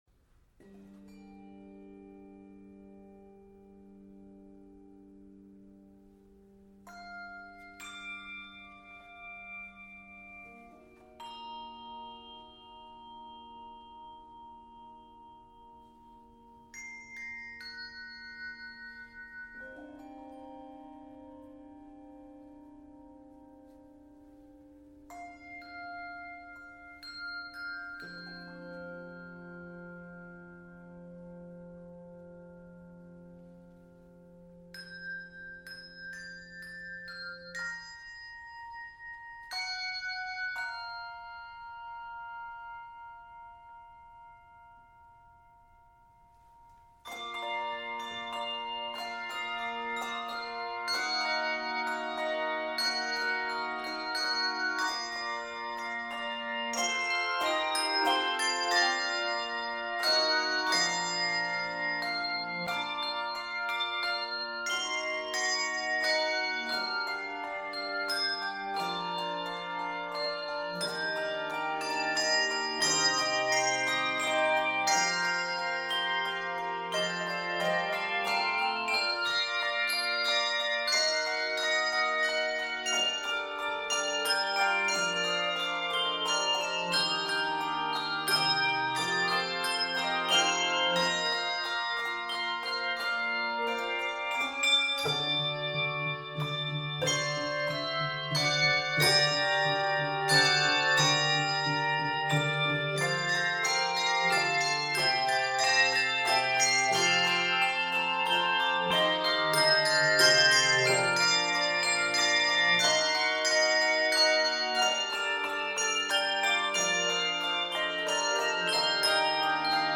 Key of Bb Major.
Octaves: 3-6